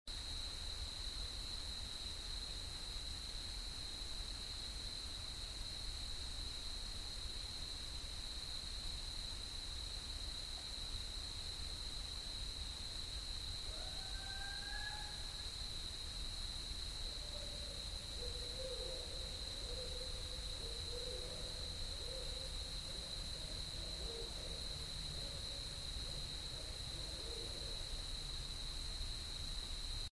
owl_4-18-08.mp3